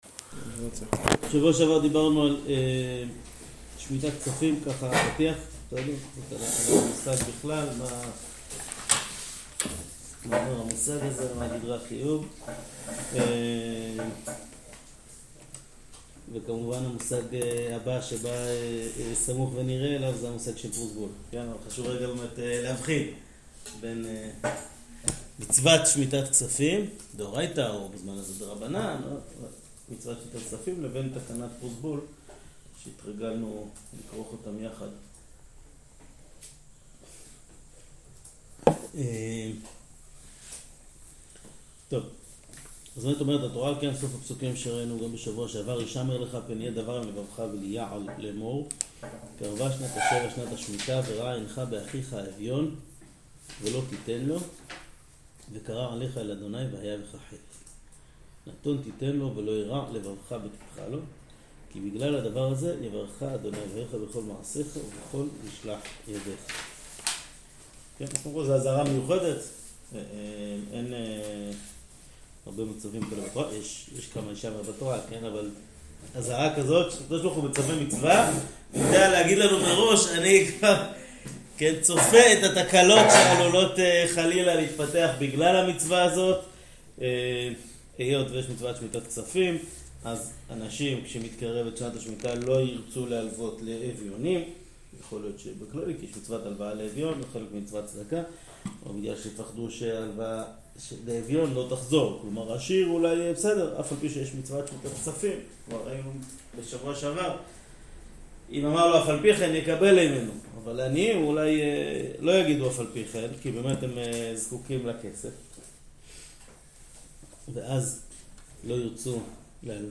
שיעור שמיטת כספים